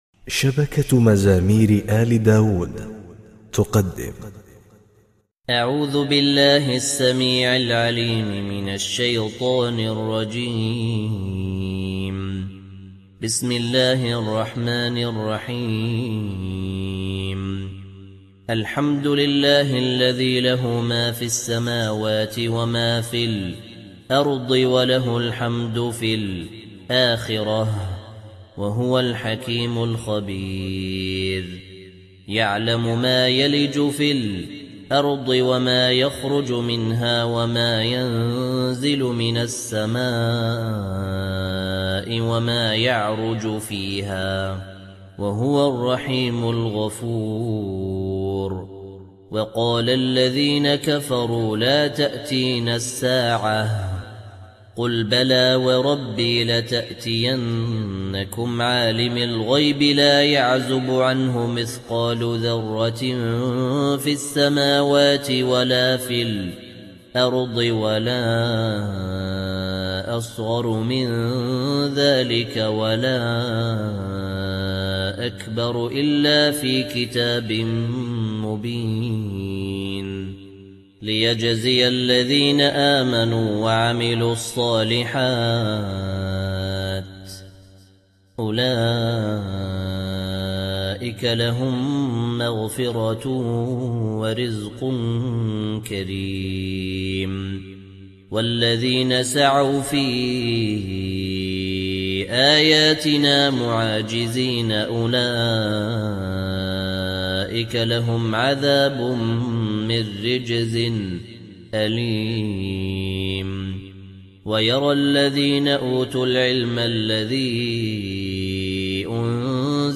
تسجيلات استوديو للشيخ مفتاح السلطني بجودة عالية